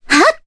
Cassandra-Vox_Attack1_jp.wav